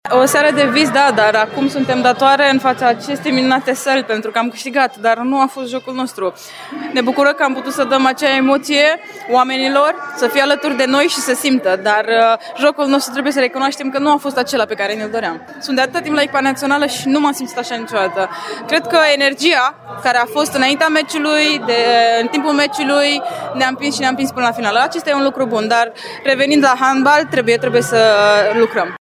Căpitanul Lorena Ostase a declarat că mai sunt lucruri de reparat:
Lorena-Ostase-trebuie-sa-mai-lucram.mp3